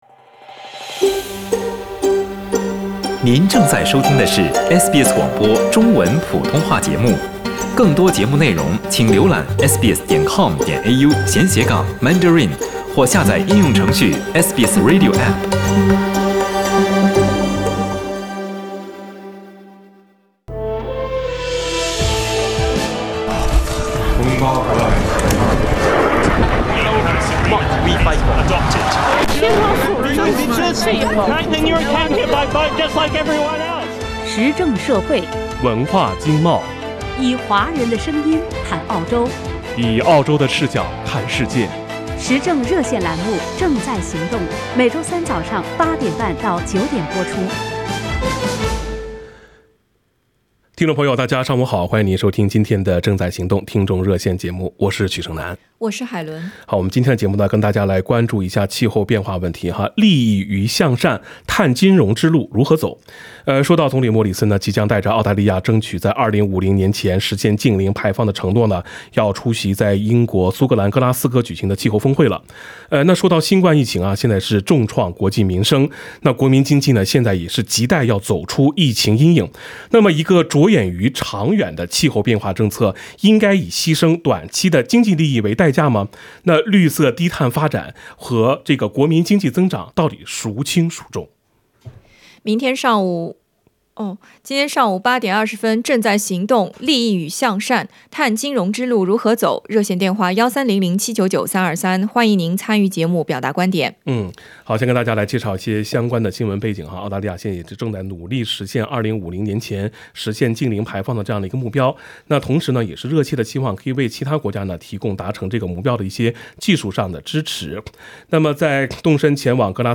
参与《正在行动》热线的听众普遍表示，气候变化是一个被各国政治正确而放大化的议题。